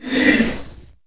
battlemage_attack1.wav